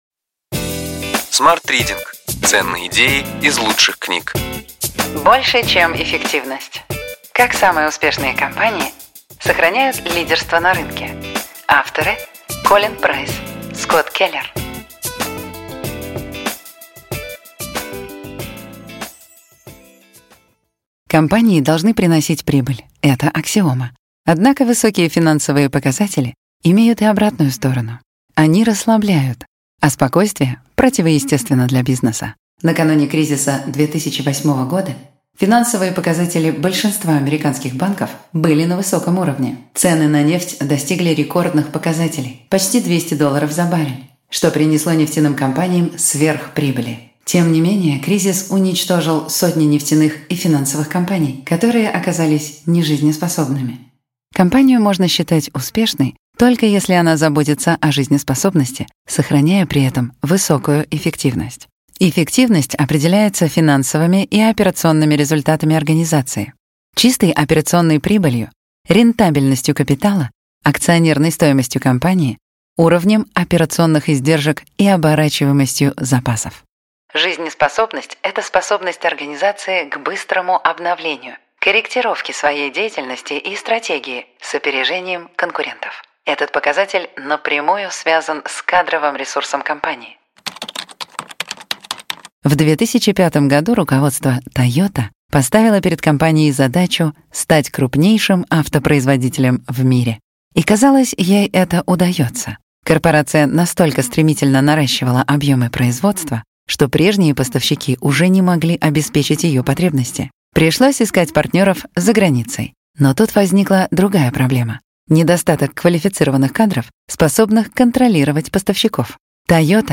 Аудиокнига Ключевые идеи книги: Больше чем эффективность. Как самые успешные компании сохраняют лидерство на рынке.